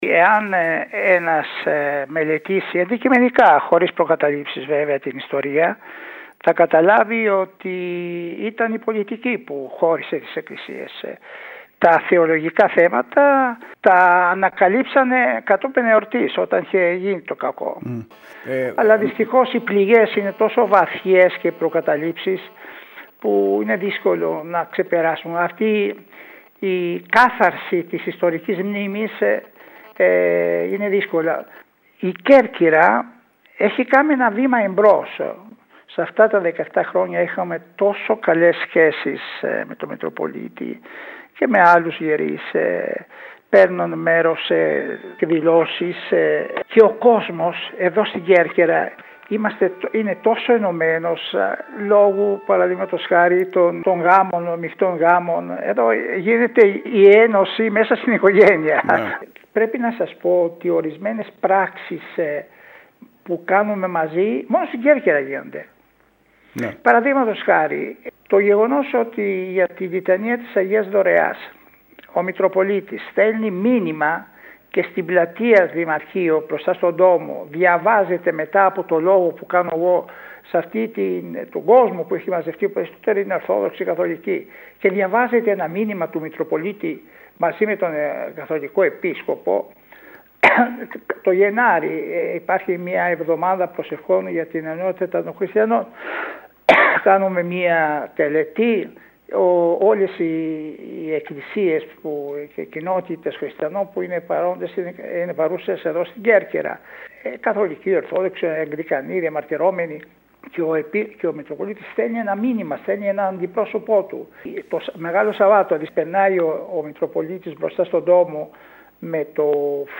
Λίγο πριν παραδώσει τον αρχιεπισκοπικό θρόνο, ο Σεβασμιώτατος Ιωάννης Σπιτέρης, μίλησε στην ΕΡΤ Κέρκυρας, για τα 17 χρόνια της διακονίας του, τα έργα που πέτυχε και αφορούν την αναστήλωση των τριών μοναδικών αναγεννησιακών ναών στον ελληνικό χώρο, του Ντόμο, του Αγίου Φραγκίσκου και της Τενέδου.